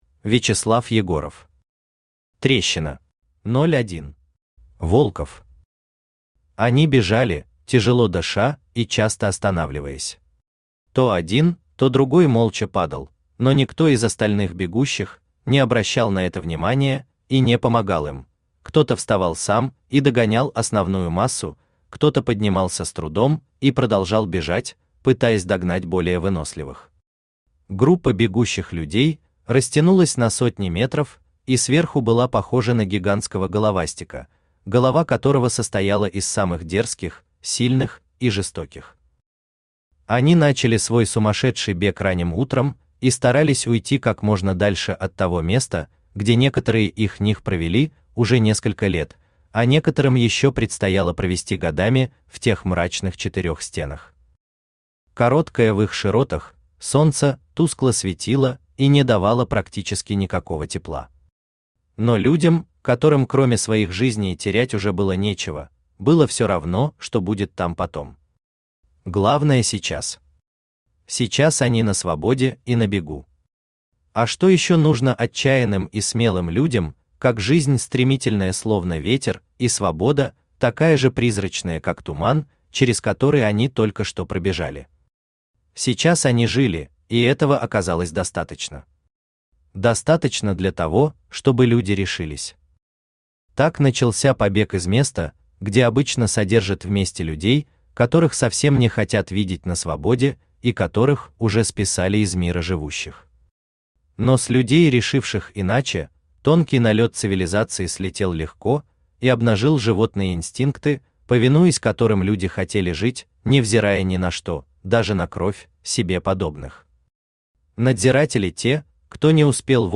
Аудиокнига Трещина | Библиотека аудиокниг
Aудиокнига Трещина Автор Вячеслав Анатольевич Егоров Читает аудиокнигу Авточтец ЛитРес.